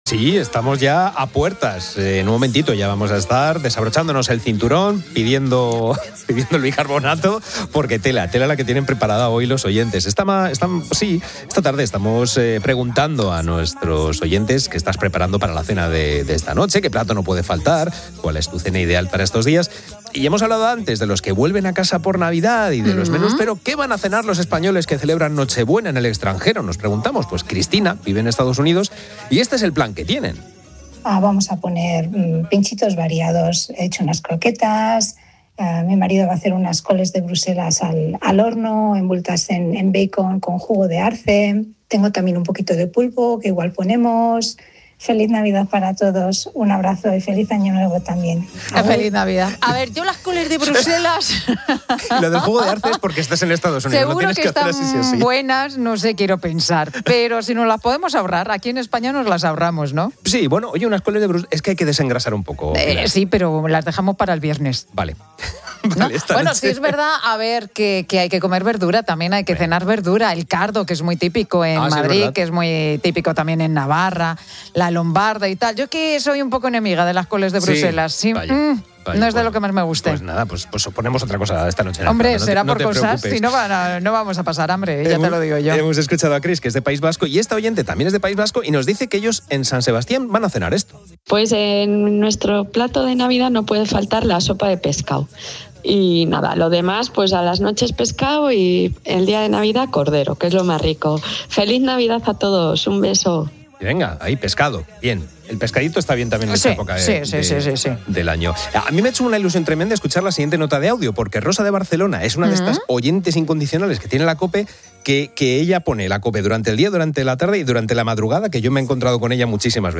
El programa 'La Tarde' de COPE repasa los variados menús de Nochebuena de sus oyentes, desde las recetas más tradicionales a las más innovadoras
La innovación de las coles de Bruselas con jugo de arce ha generado un simpático debate en el estudio.
Desde San Sebastián, una oyente del País Vasco ha afirmado que en su mesa "no puede faltar la sopa de pescado".